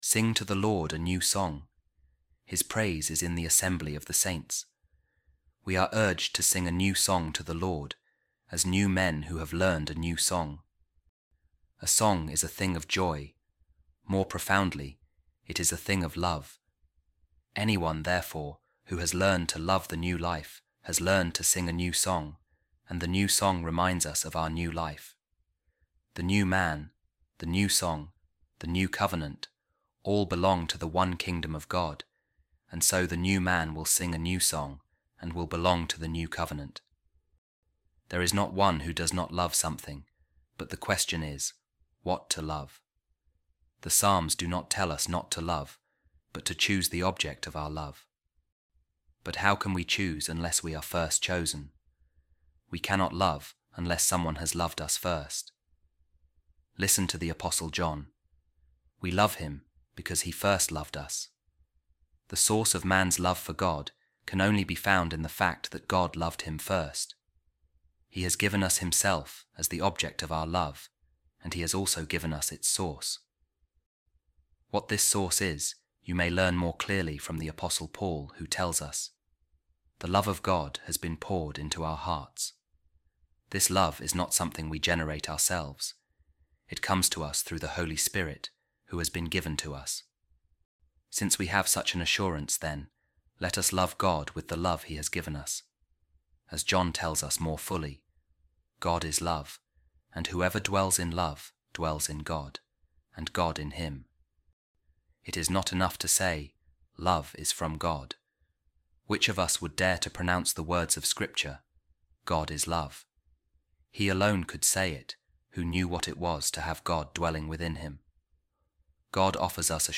A Reading From The Sermons Of Saint Augustine | Let Us Sing To The Lord A Song Of Love